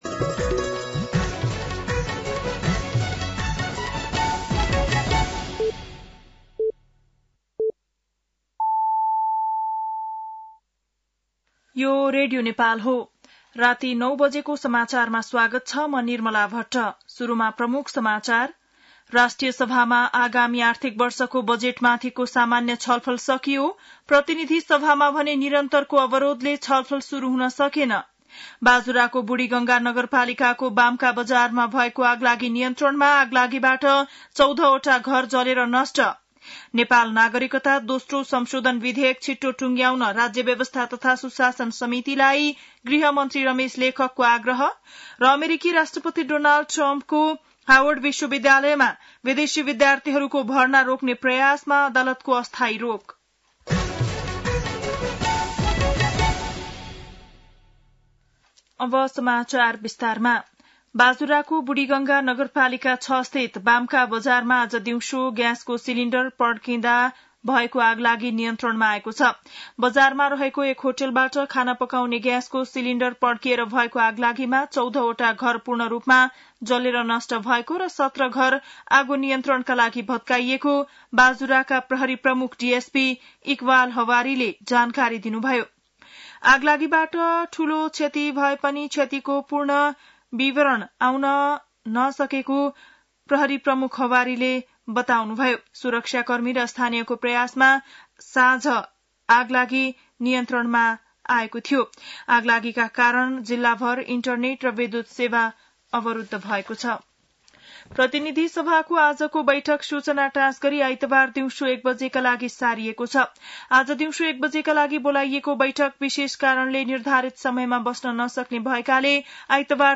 बेलुकी ९ बजेको नेपाली समाचार : २३ जेठ , २०८२
9-pm-nepali-news-2-23.mp3